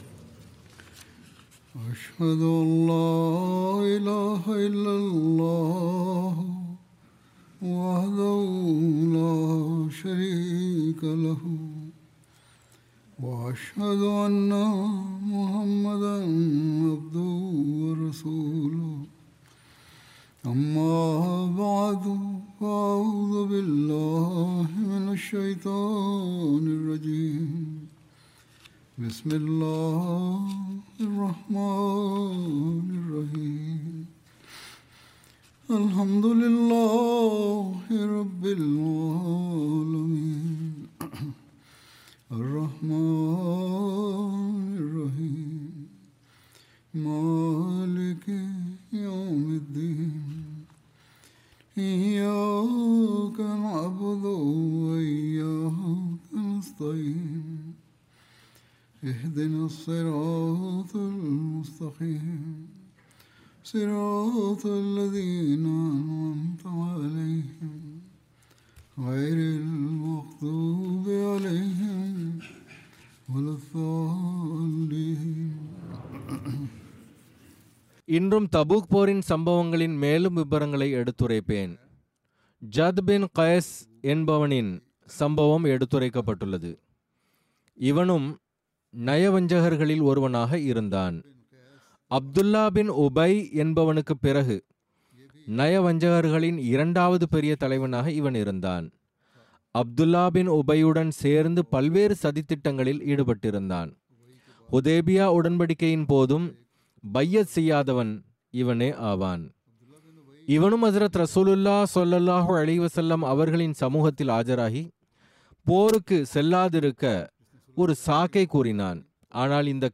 Tamil Friday Sermon by Head of Ahmadiyya Muslim Community
Tamil Translation of Friday Sermon delivered by Khalifatul Masih